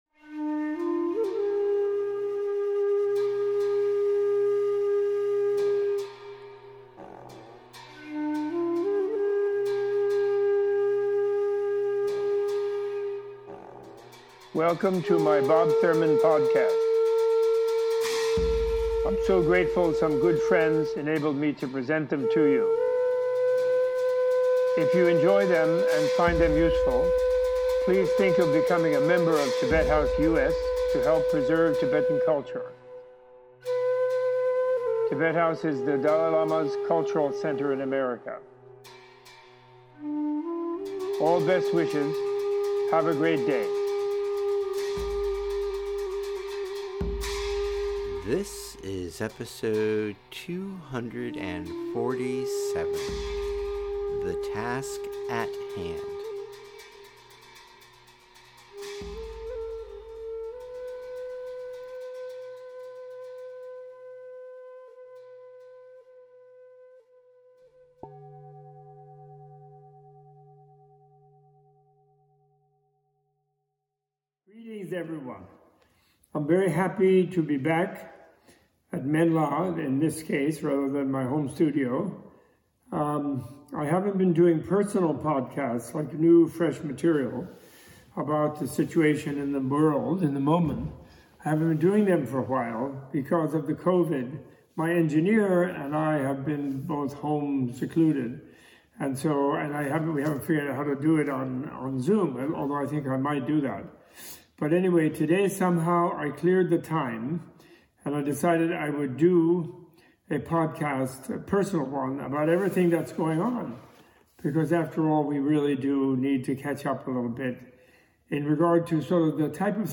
A special post election message of hope from Robert A.F. Thurman recorded November 2020 in Phoenicia, New York at Menla Retreat and Dewa Spa.